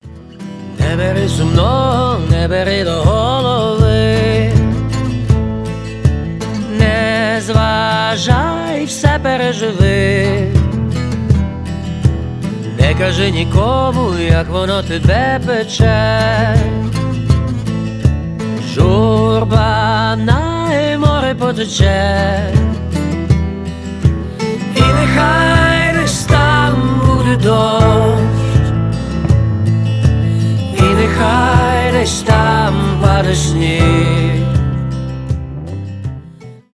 гитара , зарубежные , акустика , рок